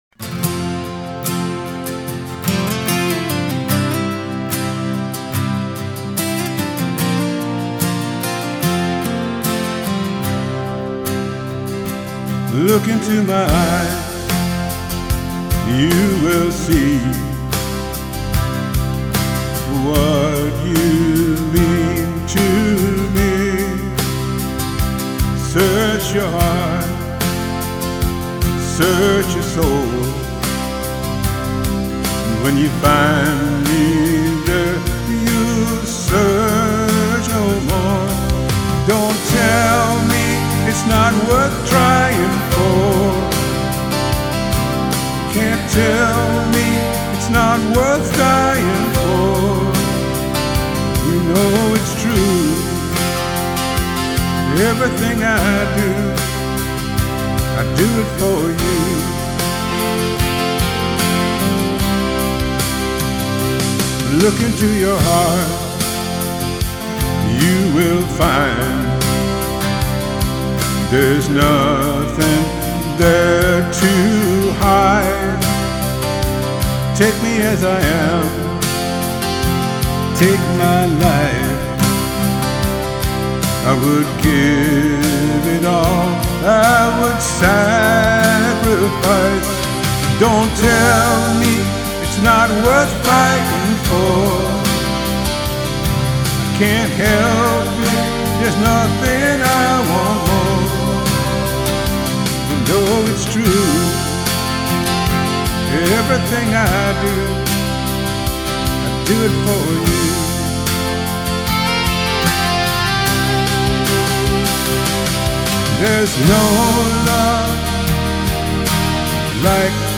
Please enjoy my cover with background instrumentation